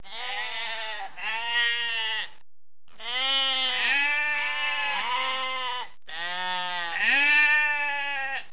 دانلود آهنگ گوسفند برای کودکان از افکت صوتی انسان و موجودات زنده
دانلود صدای گوسفند برای کودکان از ساعد نیوز با لینک مستقیم و کیفیت بالا
جلوه های صوتی